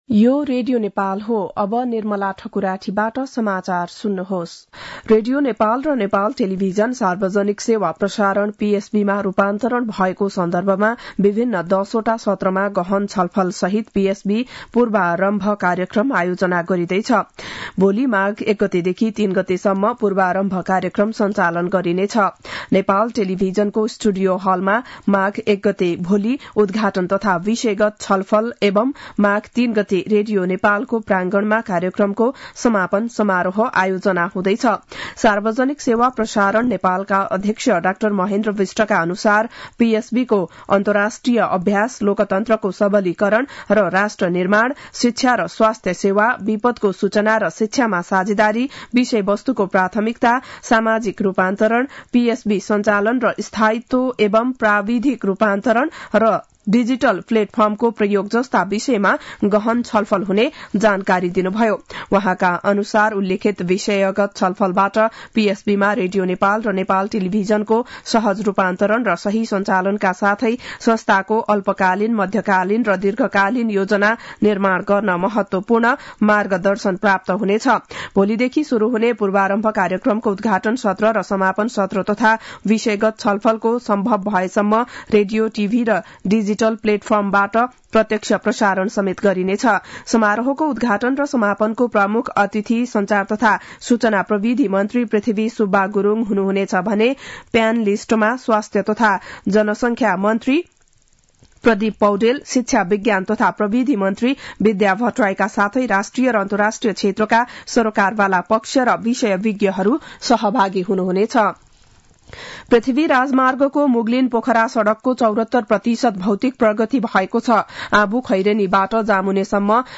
बिहान ११ बजेको नेपाली समाचार : १ माघ , २०८१